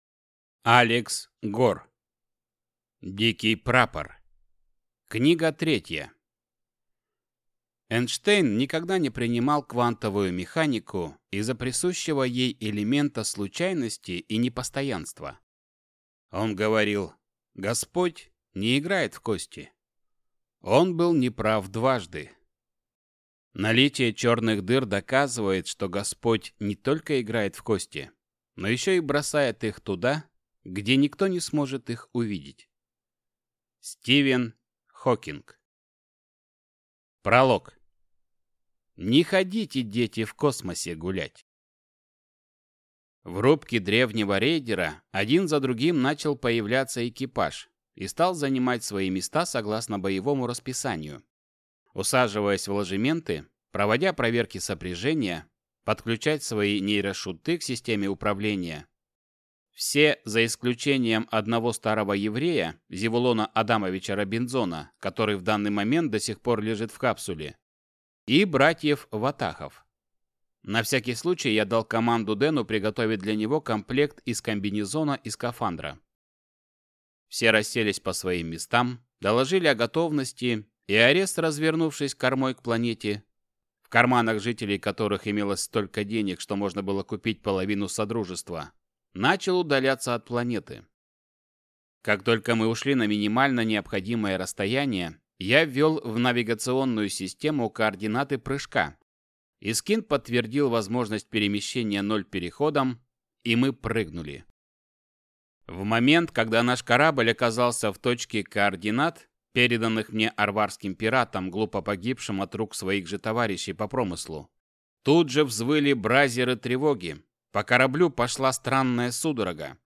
Аудиокнига Дикий прапор. Книга 3 | Библиотека аудиокниг